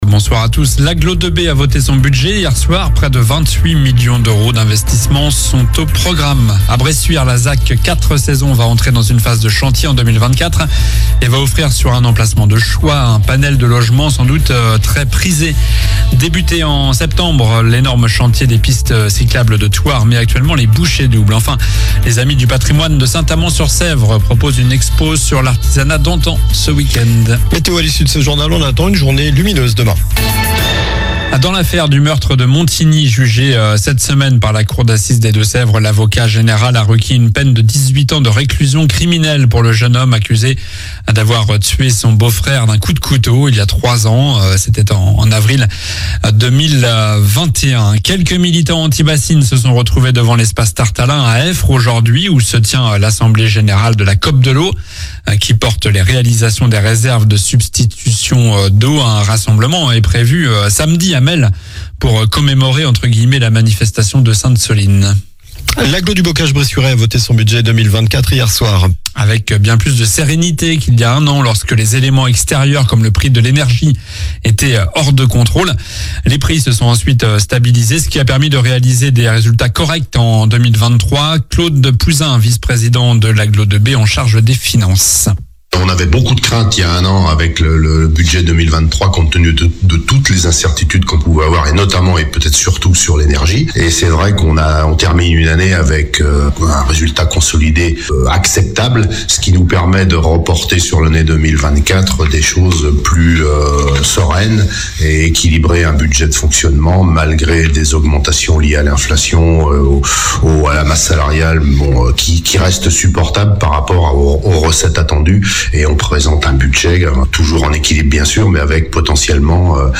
Journal du mercredi 20 mars (soir)